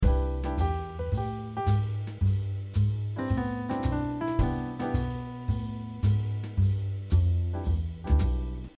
2. Use chord tones
Using-chord-tones.mp3